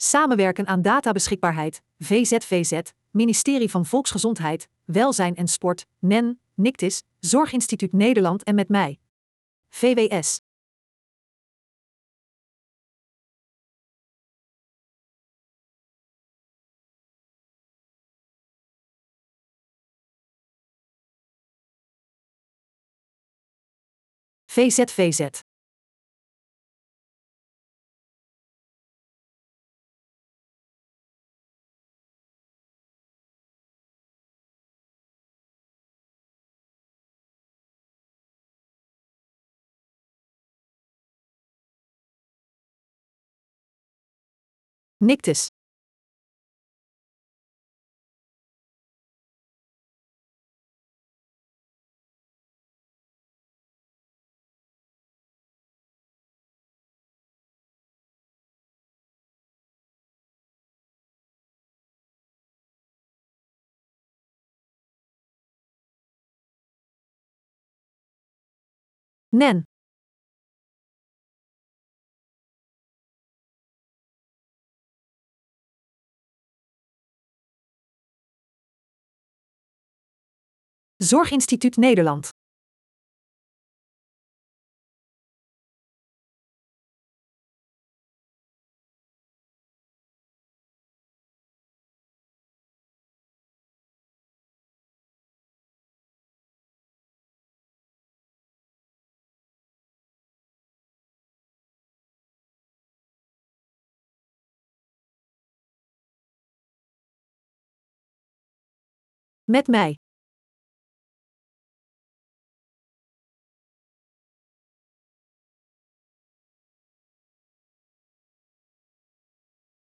Op 8, 9 en 10 april kwam het zorgveld weer samen tijdens de Zorg&ict-beurs in Jaarbeurs Utrecht. Samen met partners NEN, VZVZ, MedMij, ZIN en Nictiz presenteerde VWS daar een uitgebreid programma over gegevensuitwisseling, databeschikbaarheid en AI in de zorg.
Bekijk dan onderstaande aftermovie.